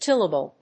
音節till・a・ble 発音記号・読み方
/tíləbl(米国英語)/